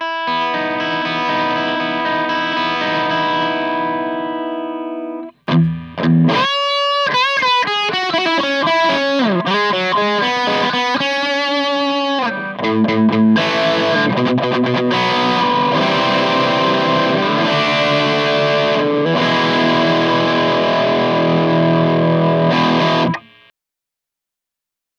キャビネットは中古でゲットしたJetCity JCA12XSにCelestion Vintage30を搭載。マイクは15年以上前に買ったSM57。
ノイズ処理も一切無し。
フレーズは25秒で、最初のアルペジオはペダルオフでアンプサウンドになります。
EMMA StinkBug
アンプの個性を邪魔しない、お気に入りのオーバードライブ。
でも癖が全く無い訳ではなく、万能なヴィンテージODといった感じです。